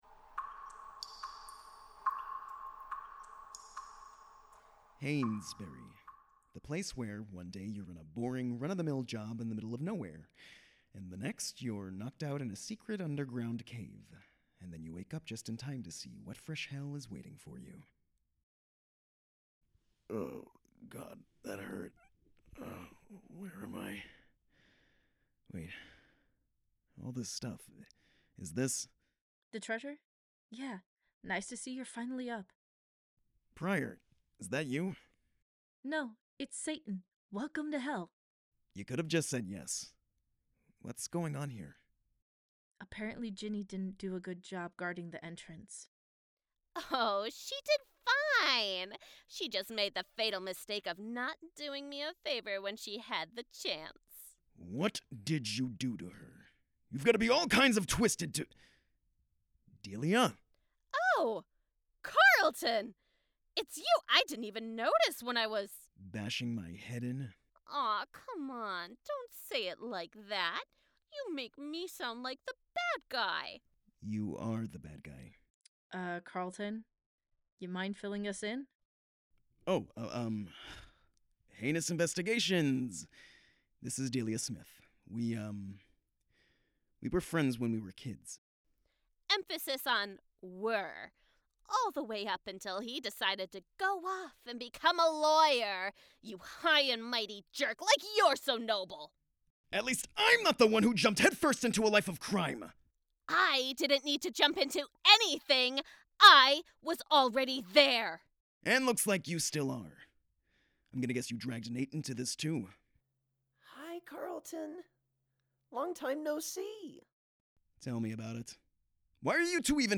*Disclaimer - The first 6 episodes of our show were when we were still finding our audio footing and our voice; we are a living production, after all!